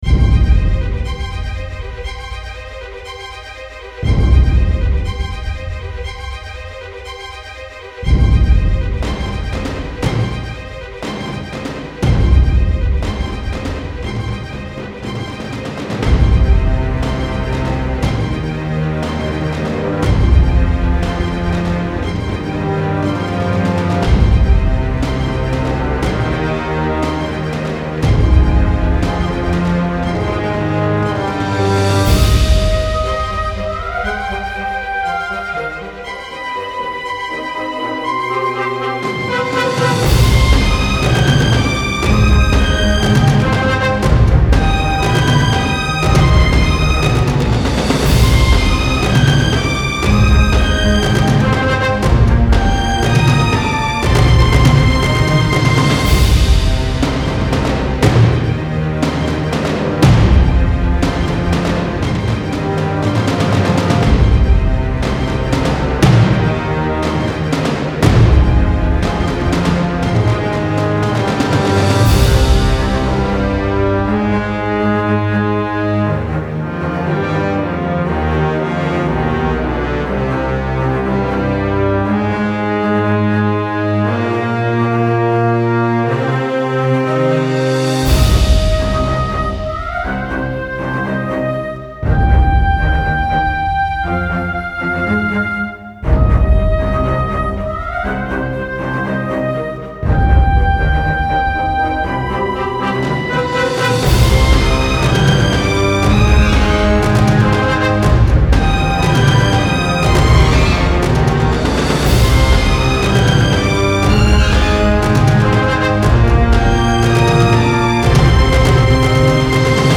Style Style Orchestral
Mood Mood AggressiveDarkEpic
Featured Featured BrassDrumsStringsWoodwind
BPM BPM 120